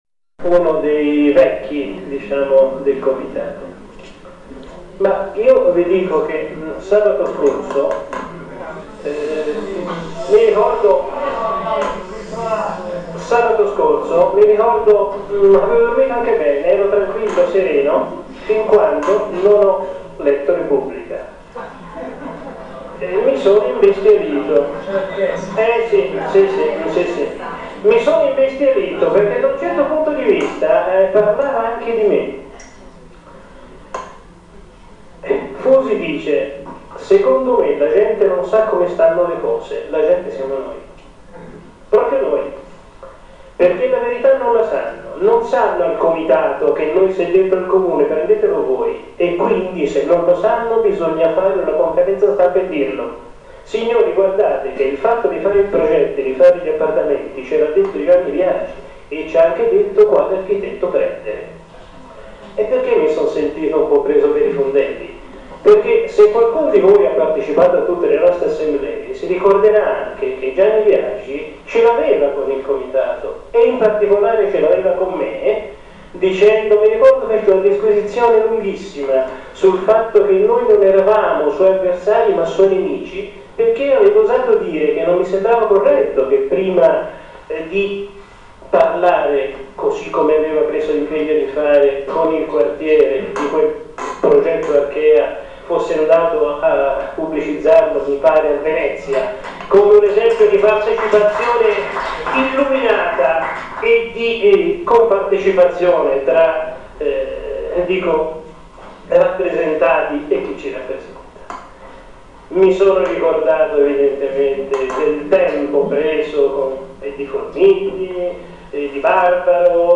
17.03.2010, l'ultima assemblea pubblica sull'Ex Panificio con la partecipazione del sindaco Renzi